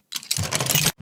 ChestLock.ogg